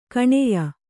♪ kaṇiya